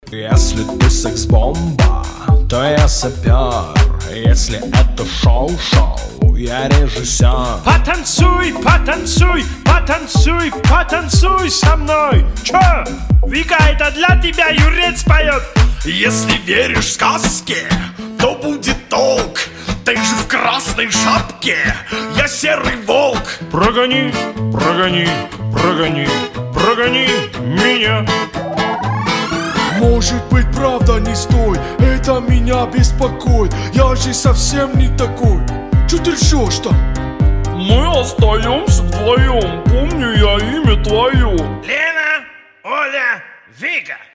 • Качество: 320, Stereo
забавные
кавер
пародия